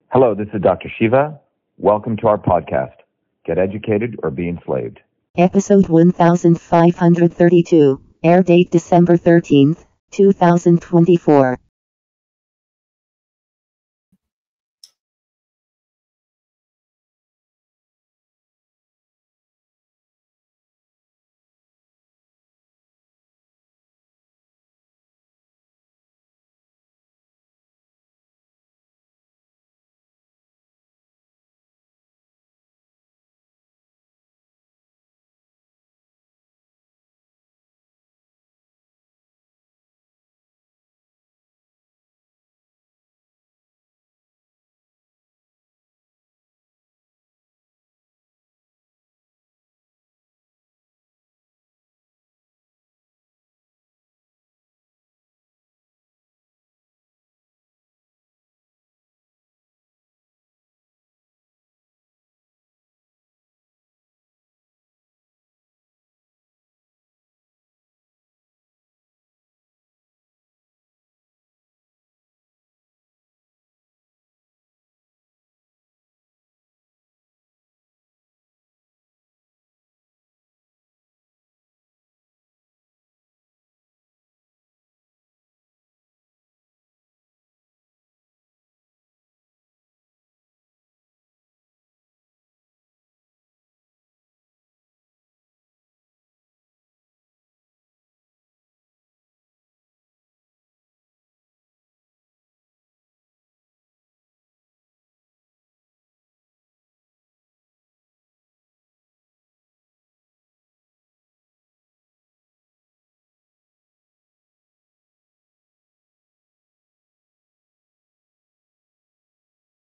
In this interview, Dr.SHIVA Ayyadurai, MIT PhD, Inventor of Email, Scientist, Engineer and Candidate for President, Talks about Syria: The Big Lesson. What We MUST Learn!